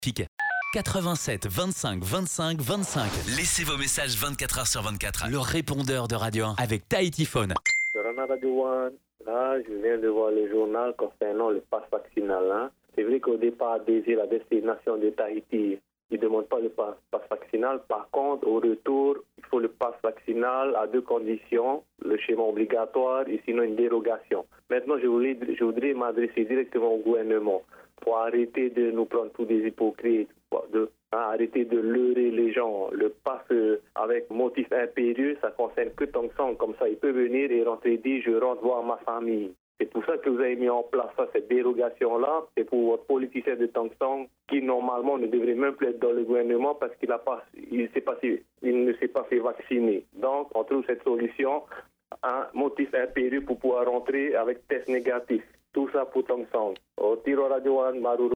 Répondeur de 6h30, le 09/02/2022